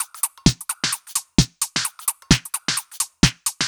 Index of /musicradar/uk-garage-samples/130bpm Lines n Loops/Beats
GA_BeatnPercE130-12.wav